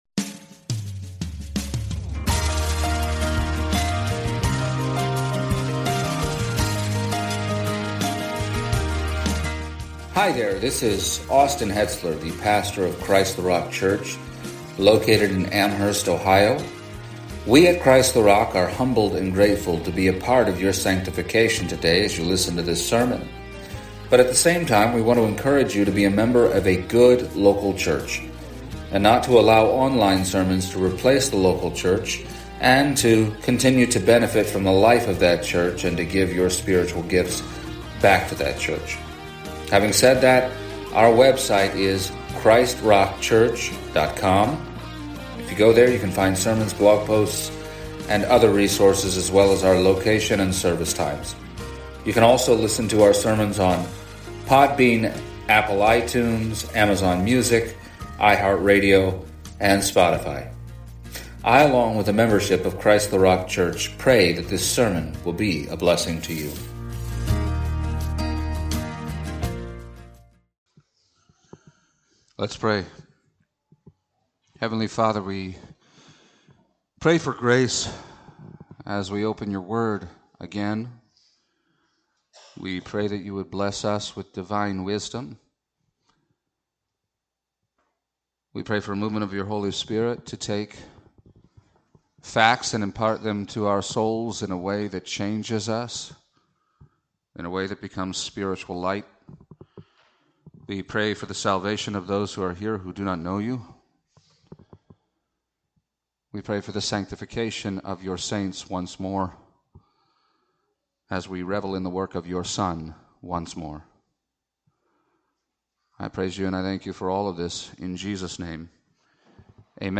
Hebrews 2:5-9 Service Type: Sunday Morning There are none elect among the angels.